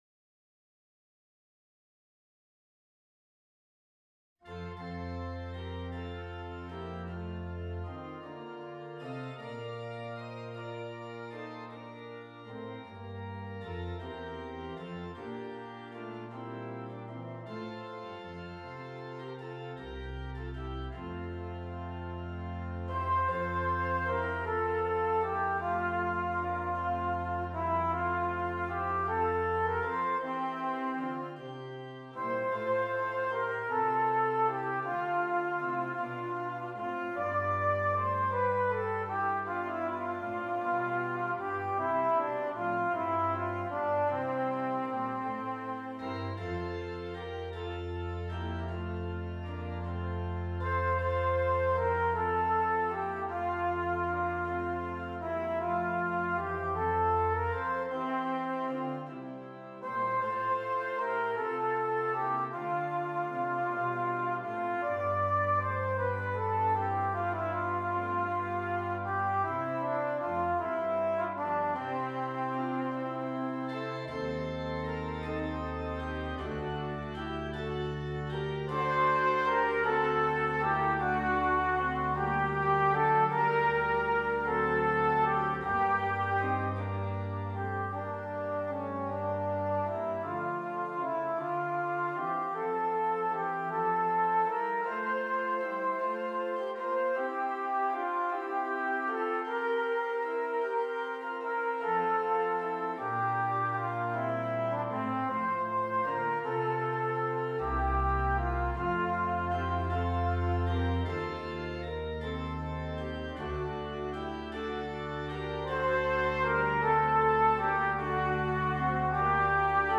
Trumpet and Keyboard